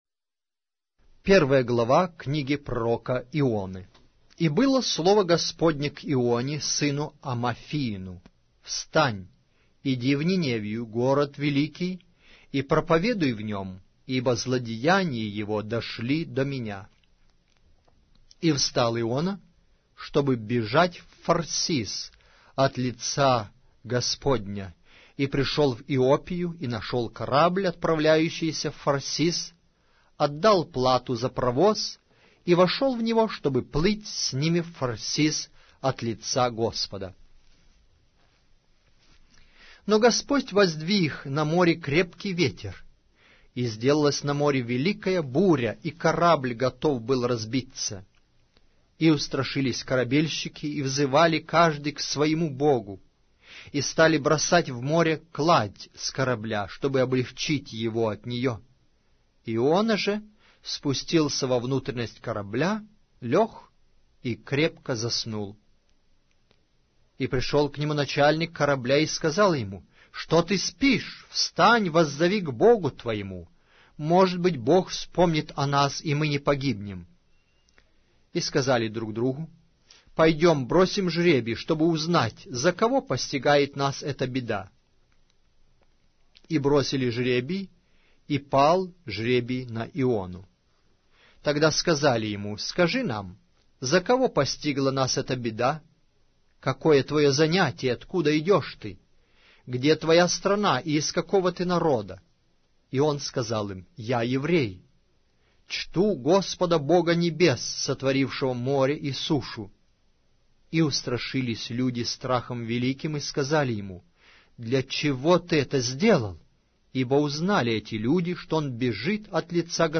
Аудиокнига: Пророк Иона